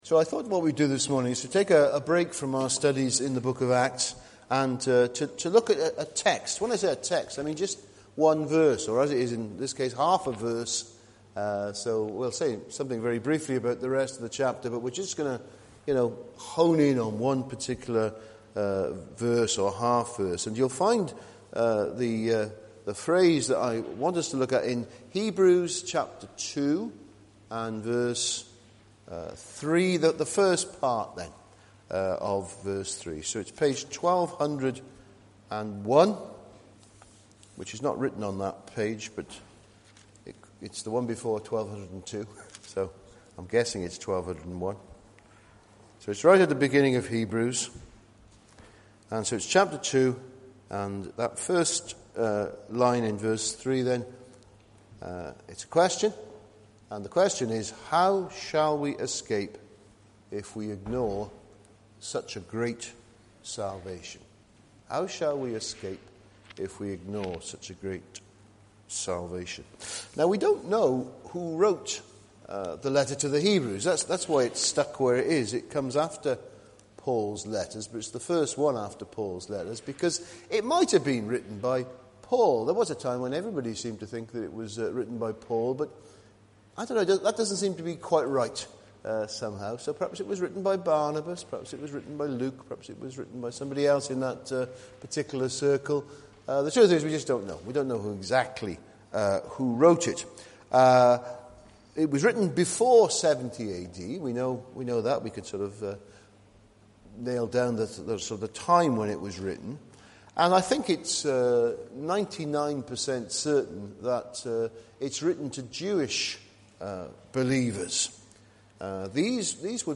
It preached quite differently to how I imagined it and included a biblical theology of salvation that people appreciated. Sermon here .